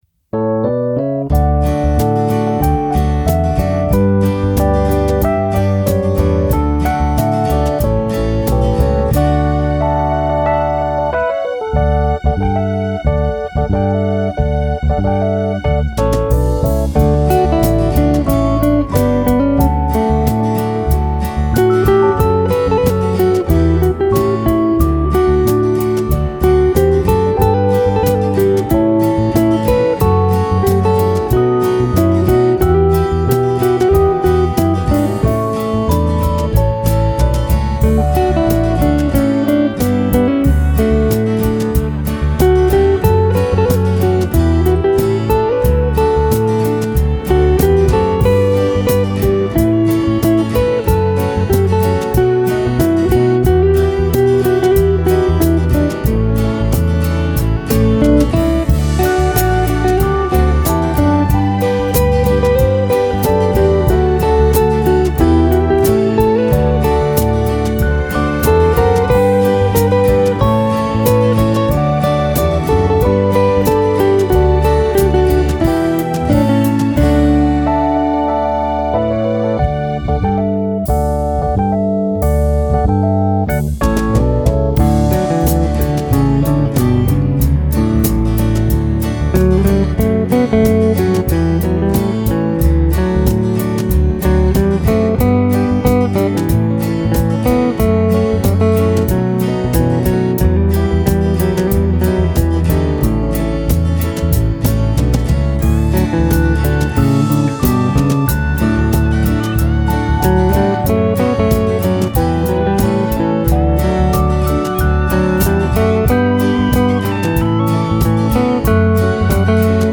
traditional and bush ballad guitarist
straightforward, clean guitar style
instrumental version